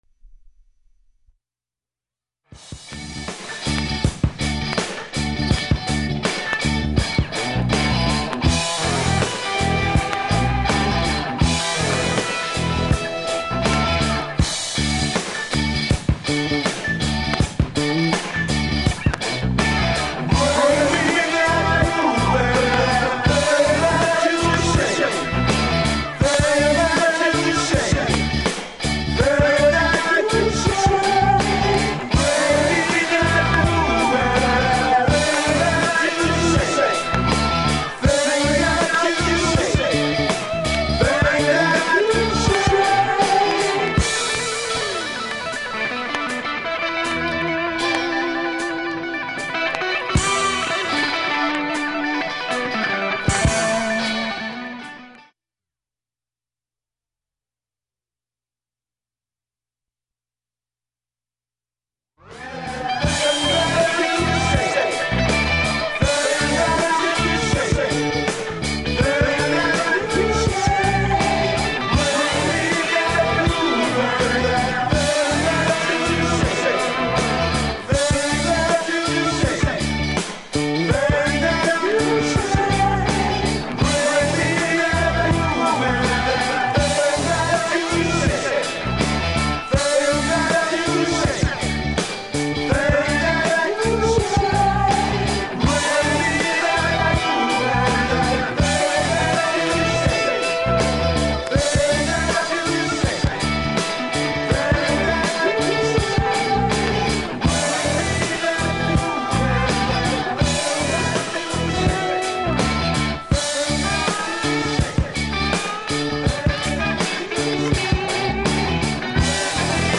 ◆盤質Ｂ面/EX　B-2プチ出たり出なかったりします。
現物の試聴（）できます。音質目安にどうぞ
◆shure m-44gステレオ針（２ｇ）での試聴です。